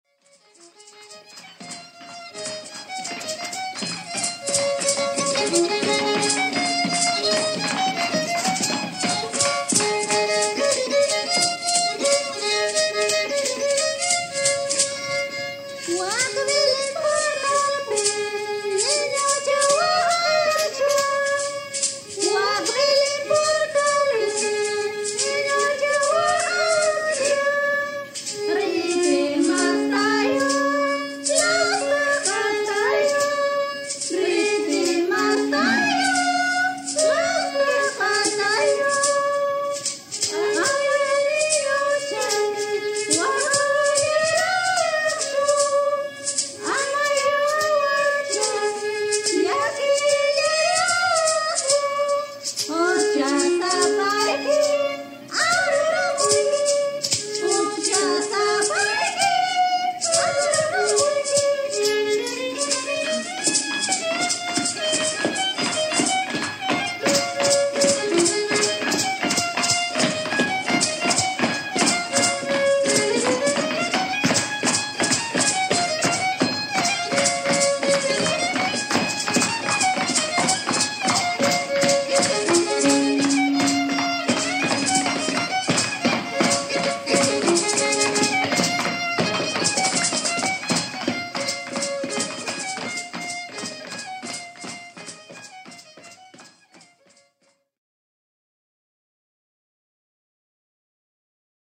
Producción sonora que presenta una selección de registros de expresiones musicales tradicionales vinculadas a la celebración de la Navidad en diversas regiones del Perú.
Cancionero, Canciones navideñas, Villancicos en quechua, Villancicos en español